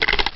07_Perc_11_SP.wav